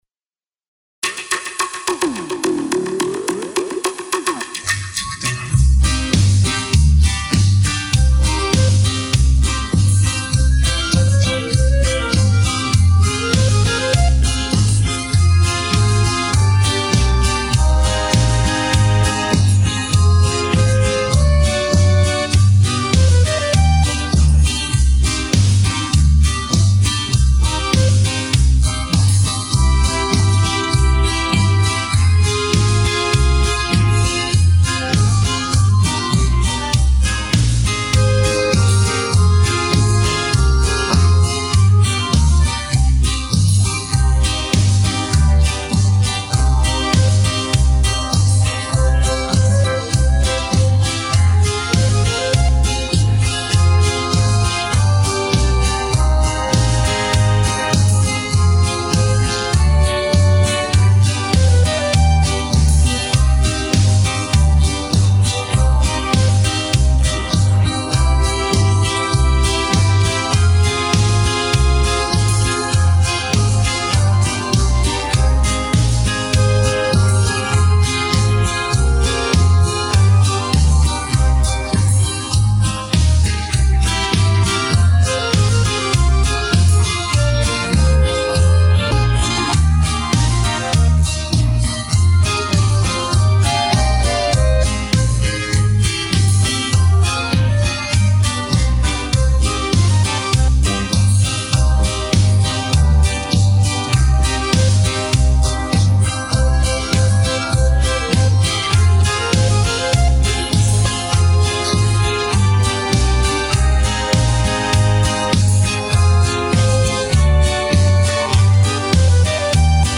ispoved-muzyikanta--minusss.mp3